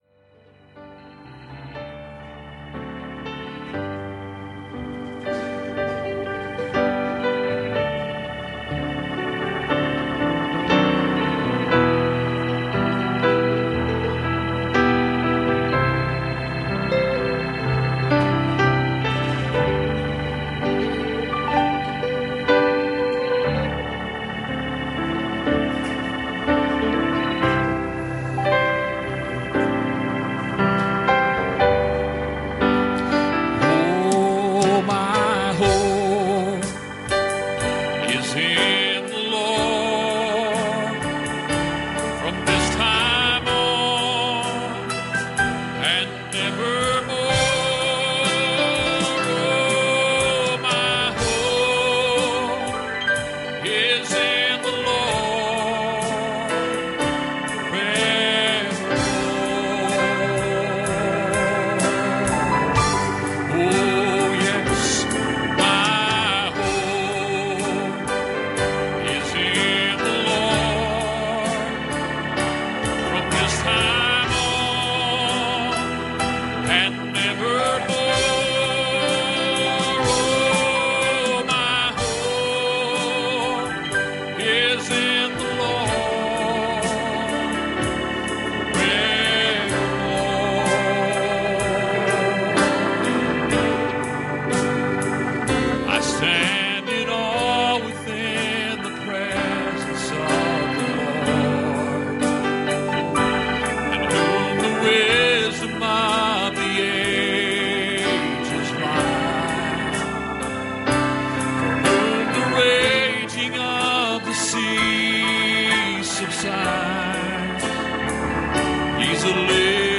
Psalm 150:1 Service Type: Sunday Morning "Feast on the Word